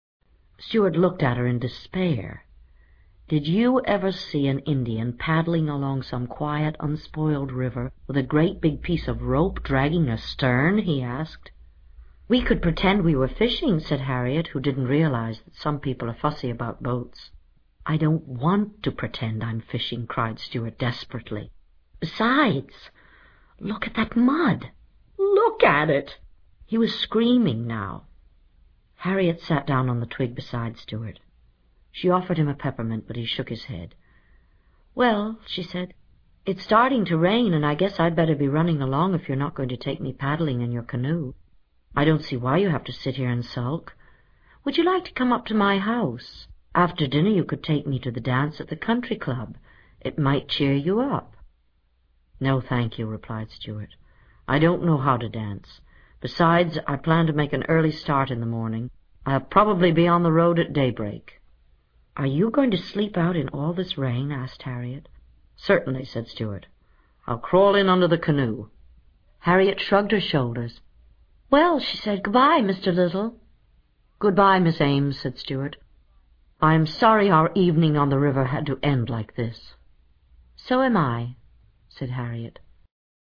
在线英语听力室精灵鼠小弟 第84期:绝望又难过的鼠小弟的听力文件下载, 《精灵鼠小弟》是双语有声读物下面的子栏目，是学习英语，提高英语成绩的极好素材。